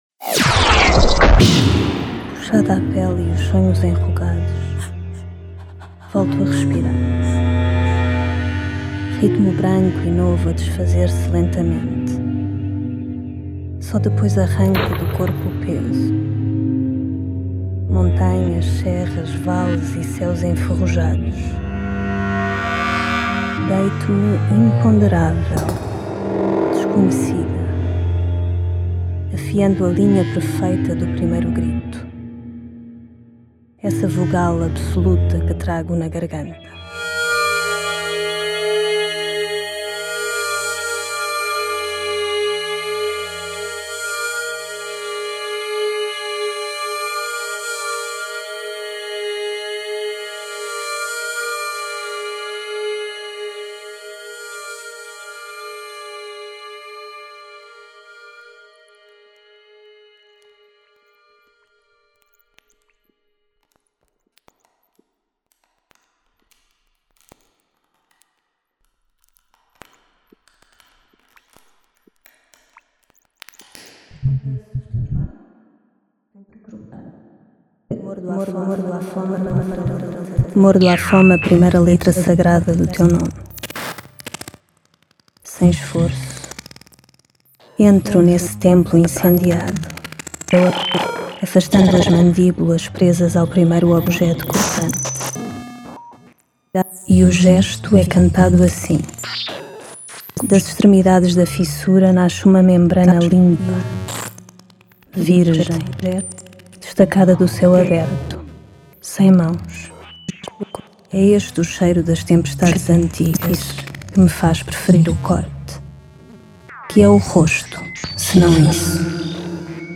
poems, voice and illustrations
electroacoustic composition
(electronics, field recordings,